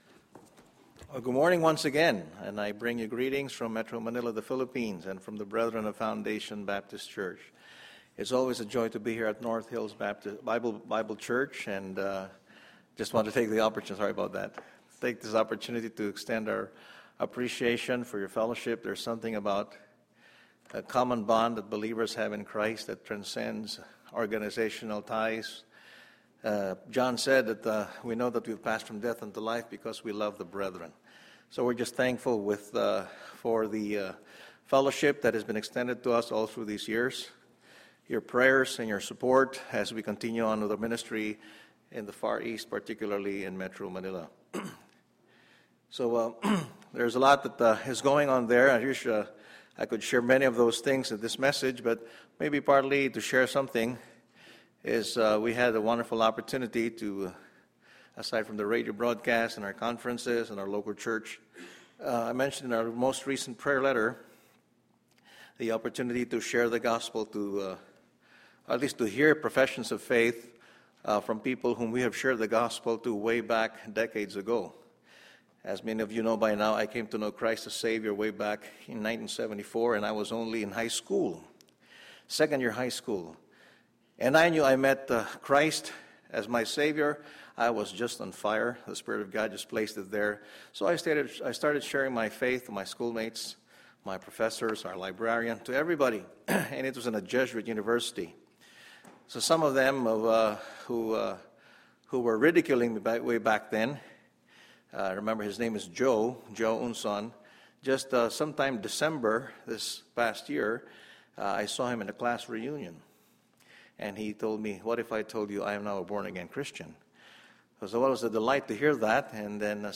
Sunday, September 16, 2012 – Morning Message